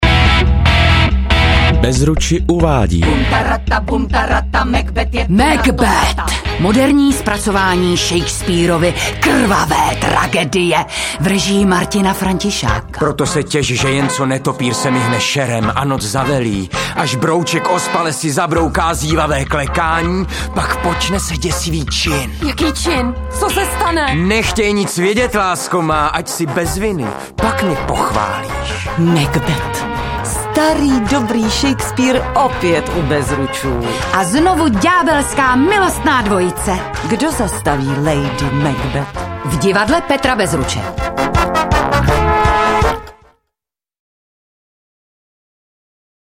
Audio-upoutávka na inscenaci Macbeth